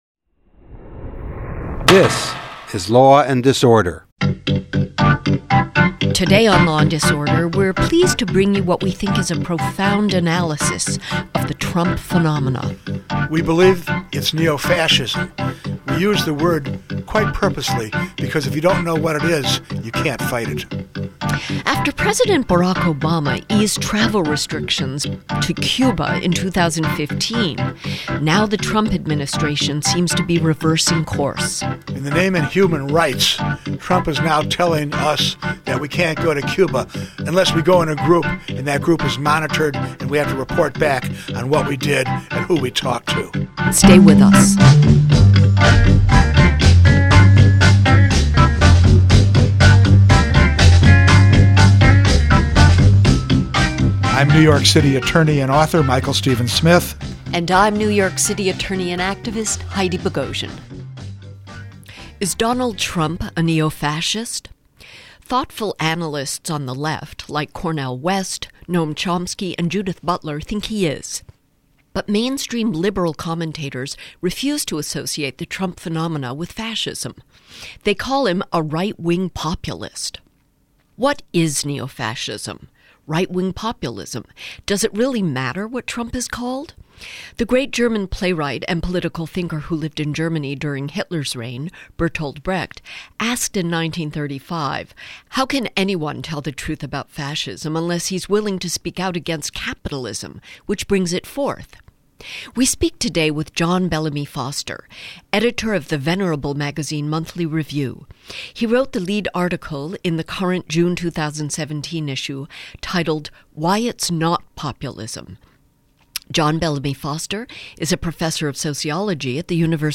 interviewed on Law & Disorder radio